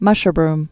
(mŭshər-brm)